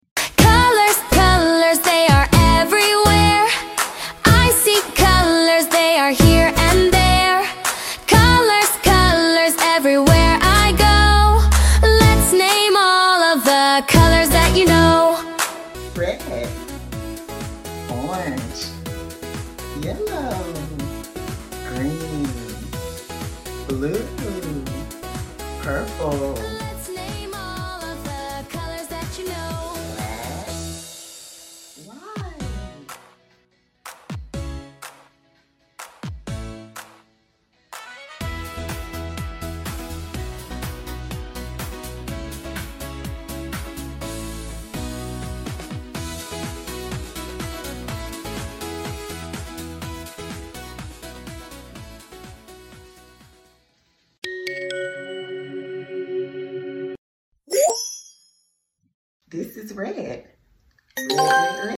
color song